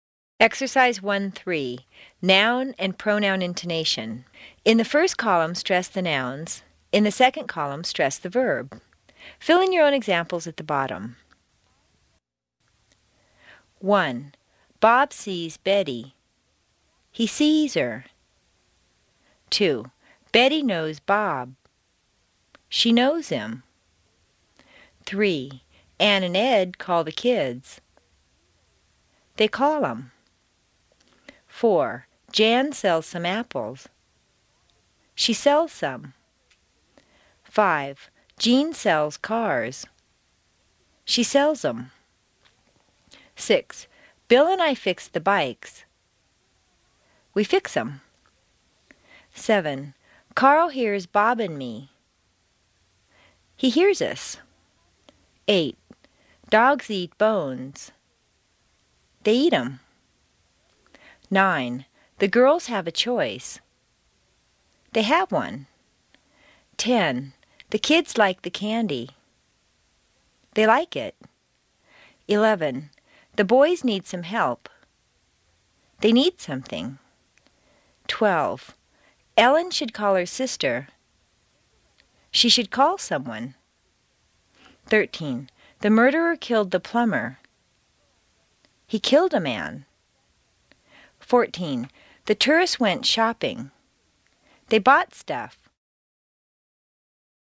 Exercise 1-3; Noun and Pronoun Intonation
In the first column, stress the nouns. In the second column, stress the verb.